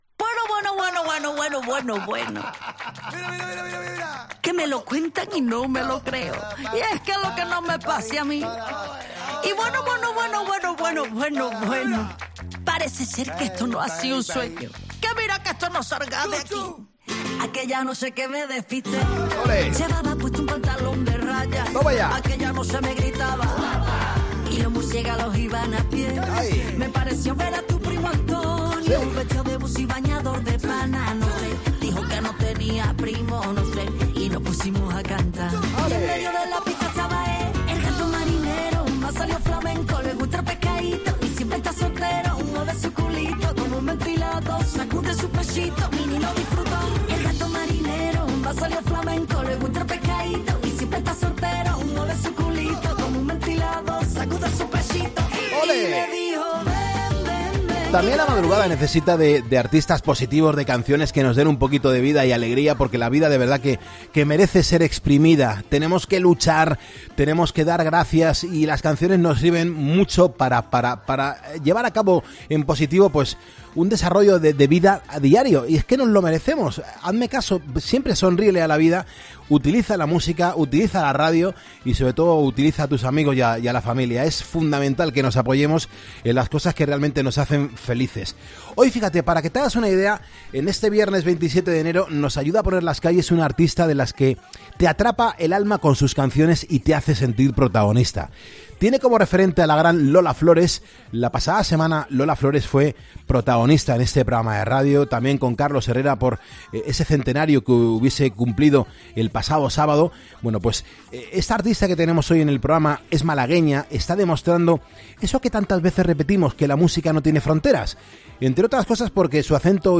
La cantante malagueña se ha pasado por 'Poniendo las Calles' para presentar su nuevo álbum, La Folcrónica